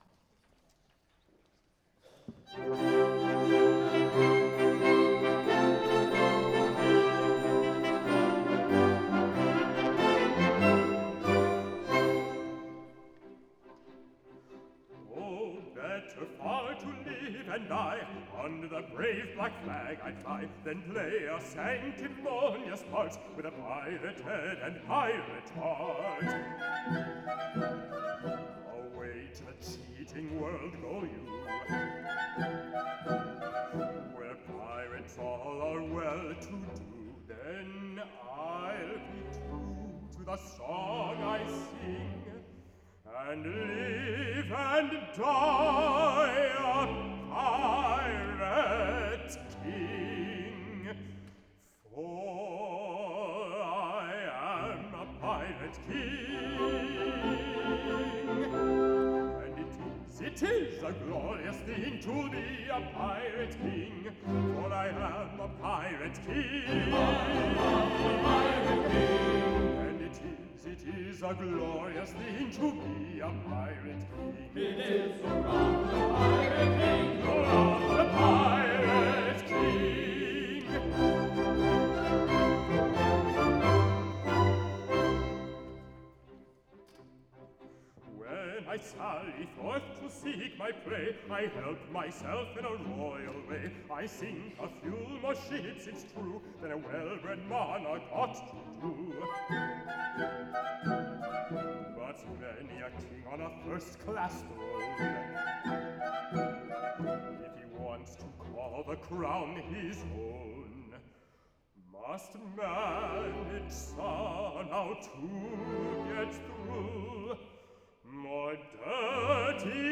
April 24, 2016 Concert Sound
Baritone Soloist